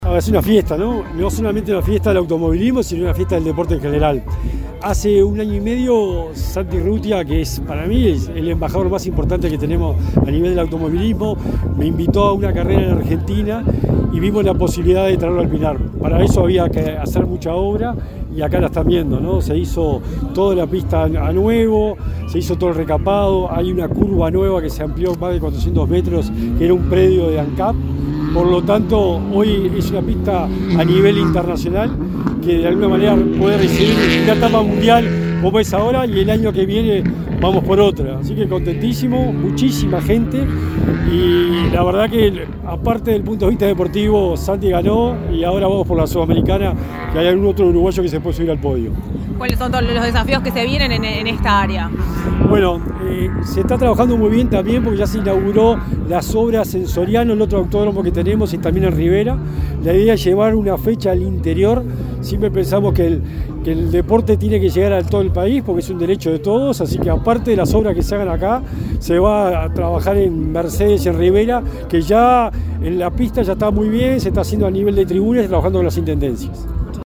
Declaraciones del secretario nacional del Deporte, Sebastián Bauzá | Presidencia Uruguay
Declaraciones del secretario nacional del Deporte, Sebastián Bauzá 20/08/2023 Compartir Facebook X Copiar enlace WhatsApp LinkedIn El secretario nacional del Deporte, Sebastián Bauzá, habló con Comunicación Presidencial durante la competencia automovilística TCR World Tour, que se realiza en el autódromo de El Pinar, en el departamento de Canelones.